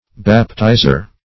Baptizer \Bap*tiz"er\, n. One who baptizes.
baptizer.mp3